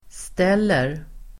Uttal: [st'el:er]